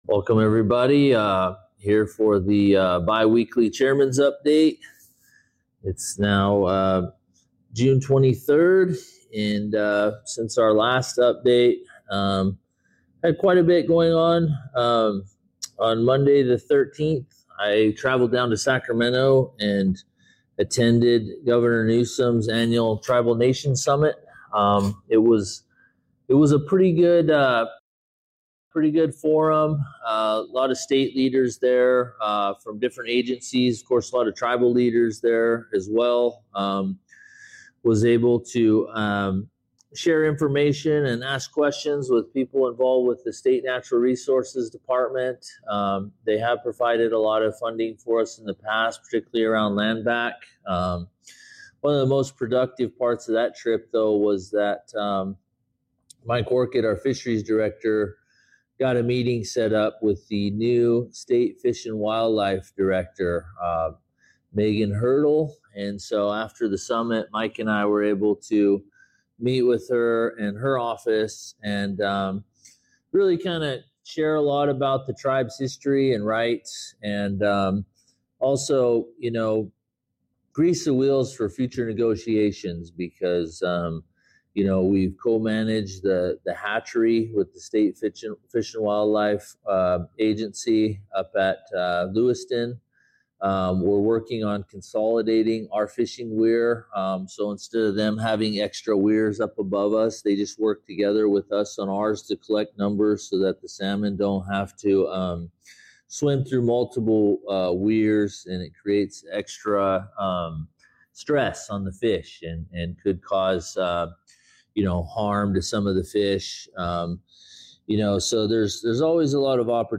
This is the recording of the Live Facebook, Chairman’s Report for April 23, 2026.